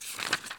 x_enchanting_scroll.2.ogg